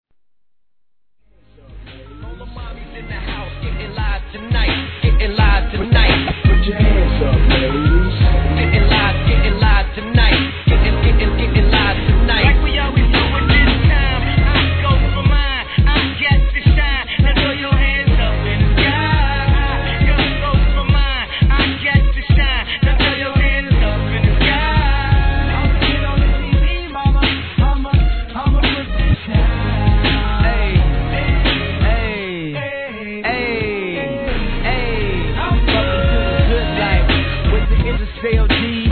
HIP HOP/R&B
フロアの反応が楽しみな驚愕REMIX!!!
BPM 85